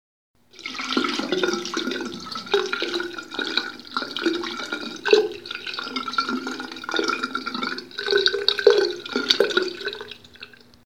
Tirando líquido por el lavabo
Grabación sonora en la que se escucha como se vacía algún tipo de líquido tirándolo por el lavabo y éste se cuela por el sumidero de la tubería con el sonido característico.
Sonidos: Agua
Sonidos: Acciones humanas